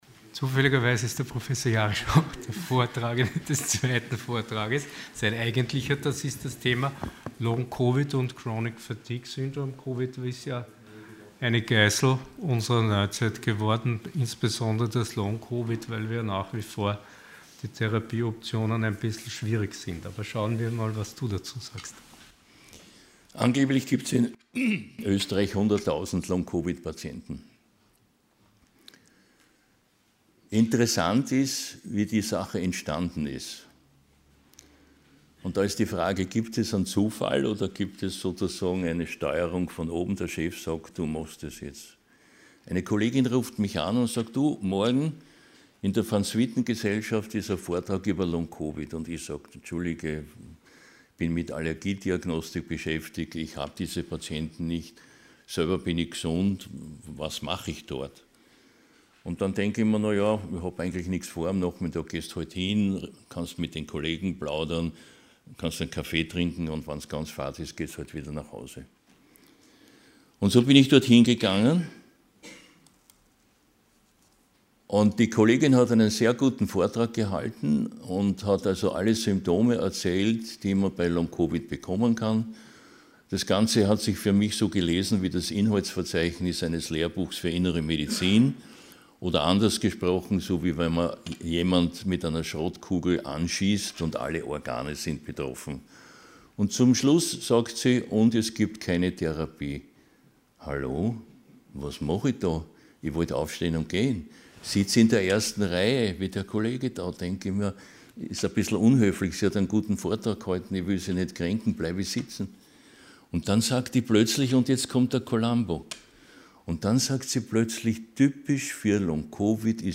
Sie haben den Vortrag noch nicht angesehen oder den Test negativ beendet.
Ort: Fach: Allgemeinmedizin Art: Fortbildungsveranstaltung Thema: - Veranstaltung: Hybridveranstaltung | Spermidin und Melatonin bei Demenz, Long COVID und COPD Moderation: